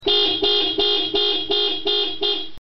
These bad boys are rated at 130+dB, a good bit over the stock horns which are stamped 113dB.
meepmeep
meepmeep.mp3